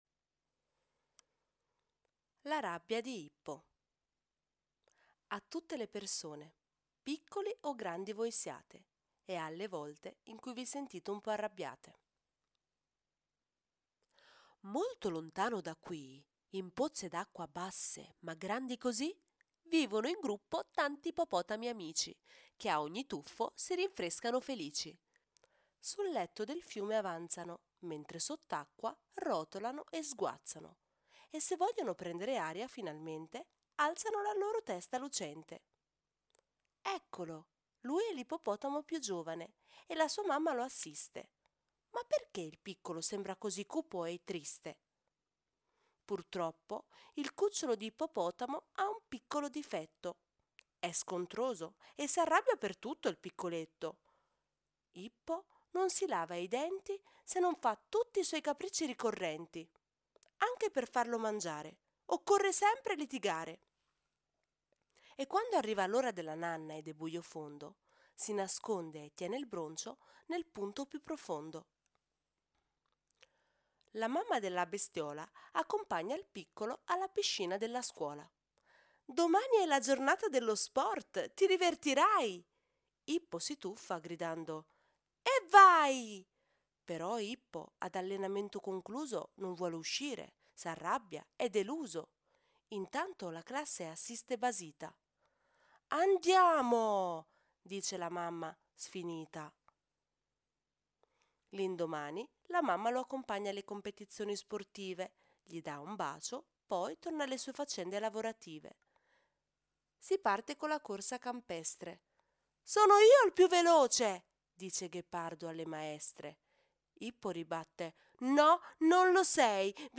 Audiolibri La Rabbia di Ippo | Gaby Books